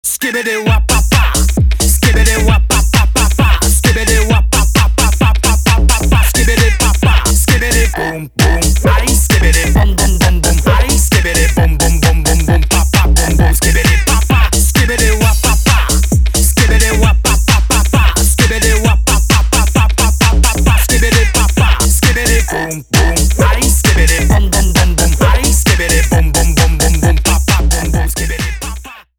Stereo
Танцевальные зарубежные ритмичные весёлые